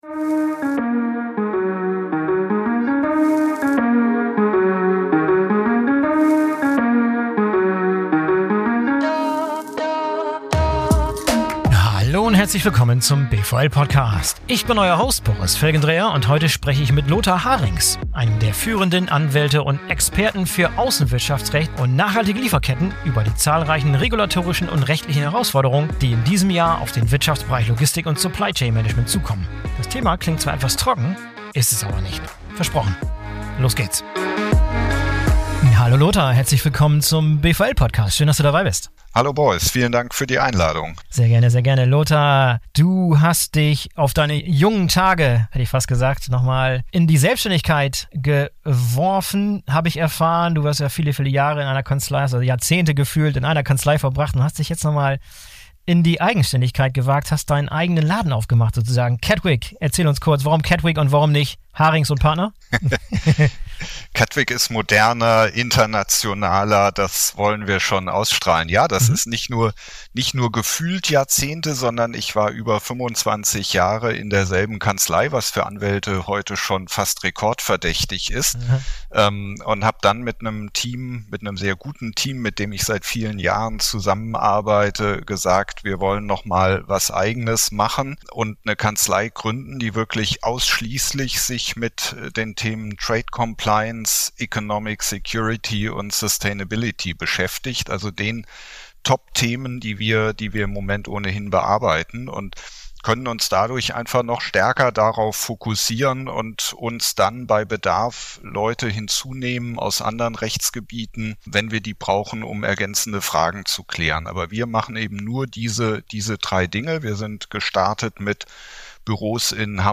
In einem ausführlichen, persönlichen Gespräch, das einen echten Blick hinter die Kulissen der Unternehmen, der Personen und deren Ideen und Herausforderungen ermöglicht.